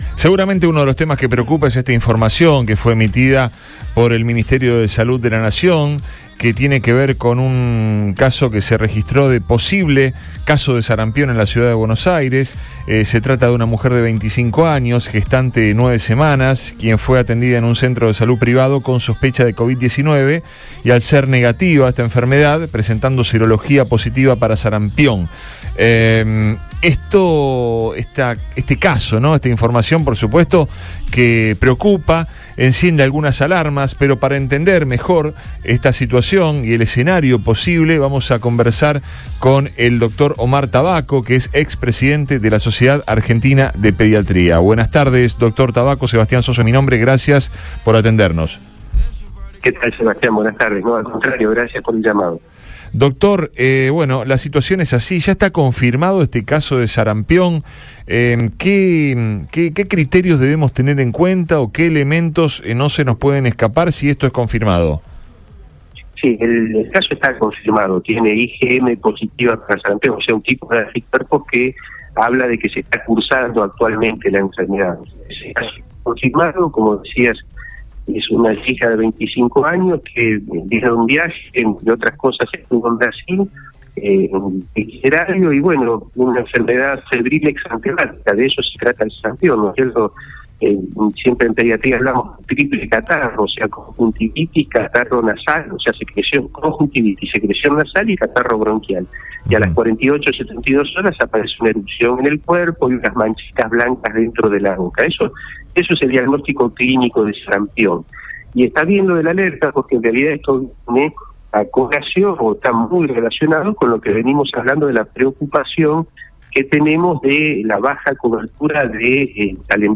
habló en Radio Boing sobre la prevención y la necesidad de inmunizar a los más chicos contra la afección.